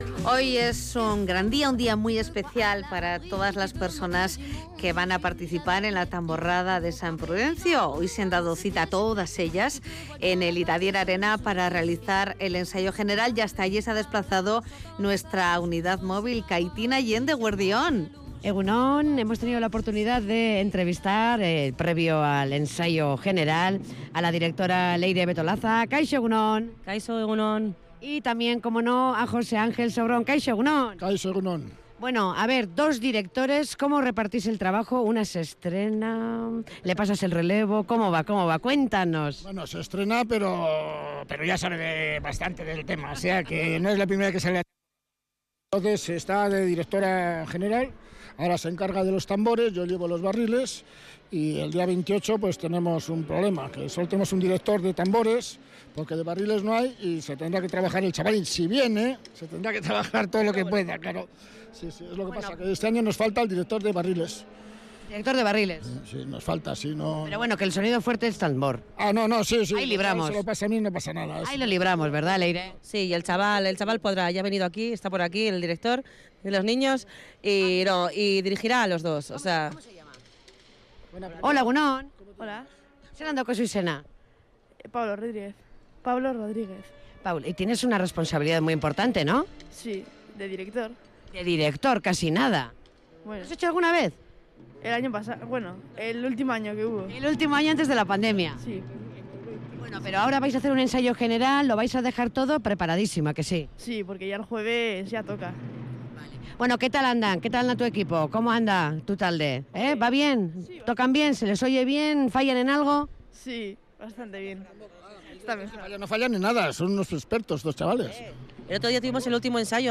Ensayo general de la Tamborrada San Prudencio 2022 en el Iradier Arena
Audio: Barriles, tambores, cantineras, mayorets, junto a directores y la Banda de música "Ezberdinak" preparados para las tamborradas de estas fiestas de San Prudencio 2022